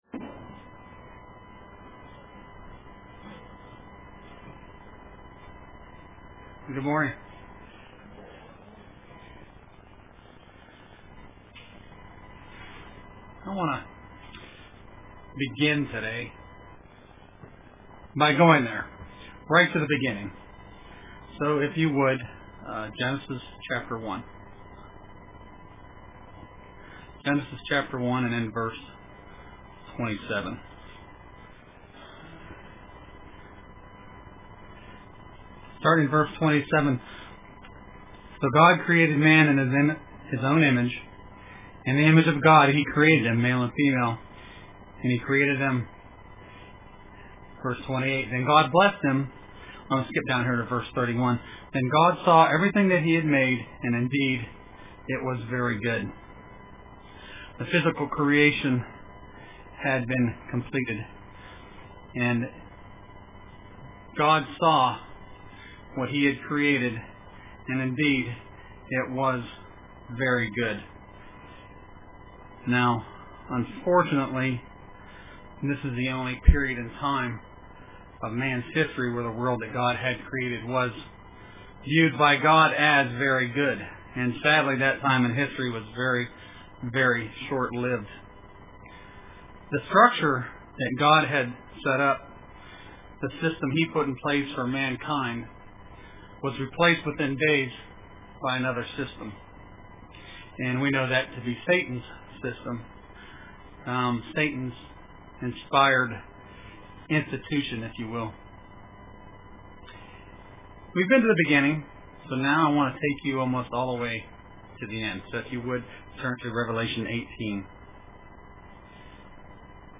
Print Living a life of faith and values unknown to the world around us UCG Sermon Studying the bible?